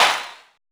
STADIUM PRC.wav